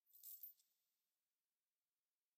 03_鬼差脚步_2.ogg